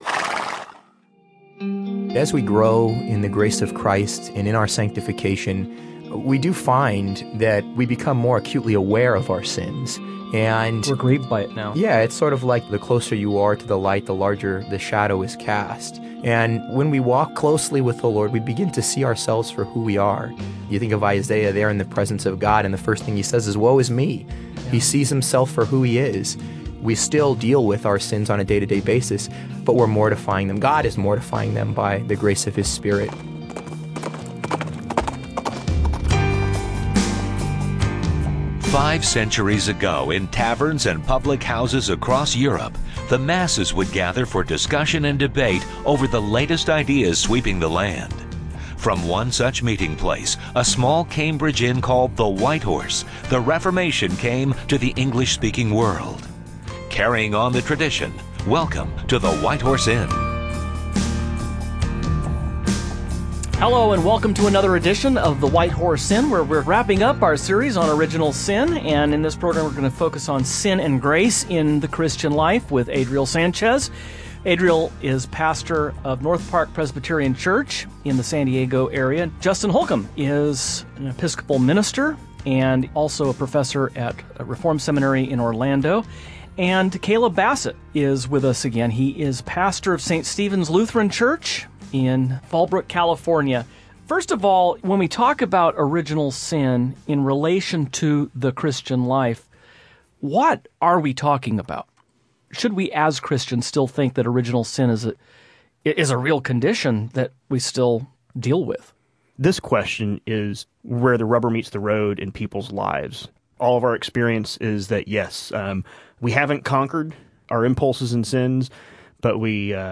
On this program the hosts will discuss what it means to be simultaneously justified and sinful by walking through…